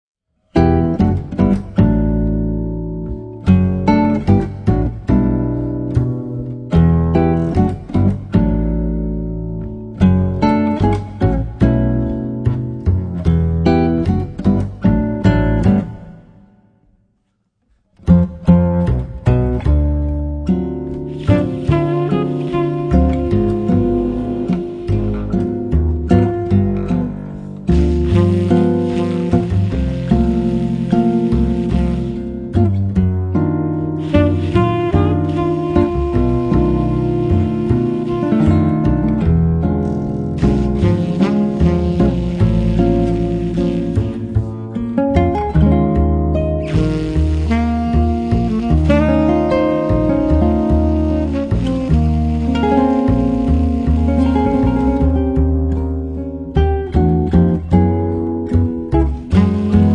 Chitarra Acustica e elettrica
sassofoni
contrabbasso